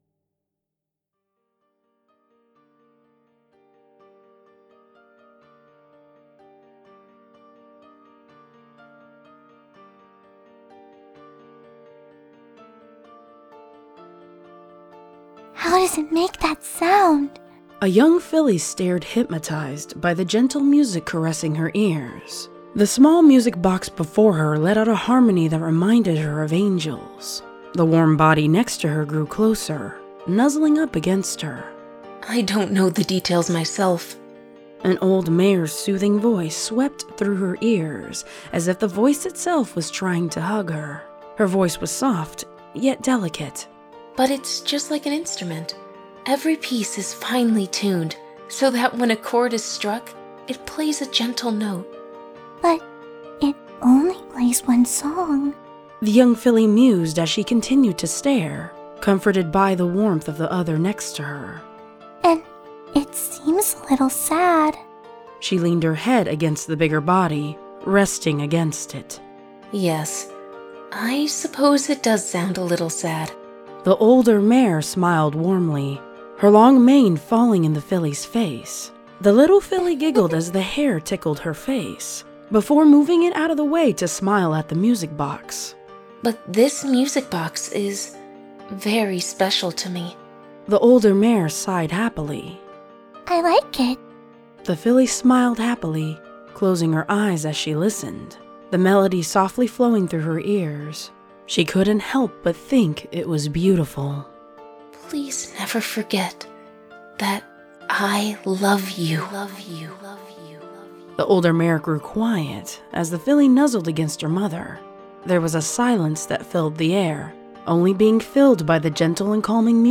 Btw, the first 15 seconds of it is music for a slow intro because I have a disclaimer at the beginning.